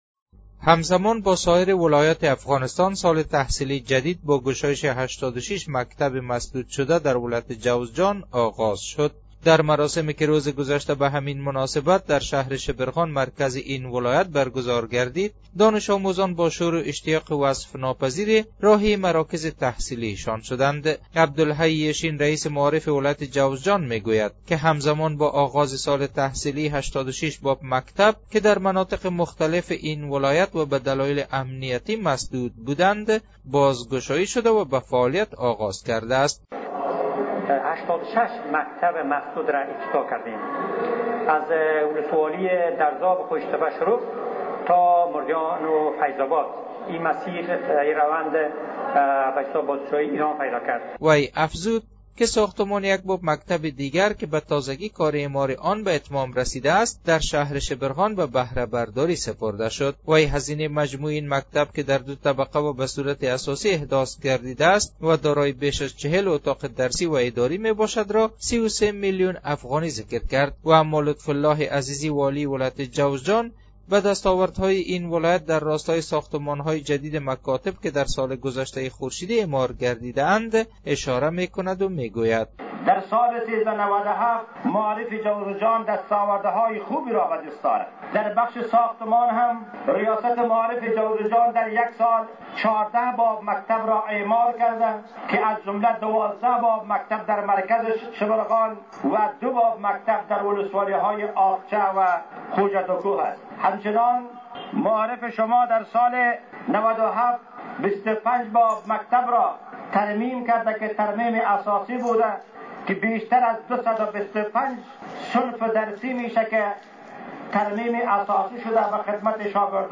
گزارش : آغاز سال تحصیلی جدید در ولایت جوزجان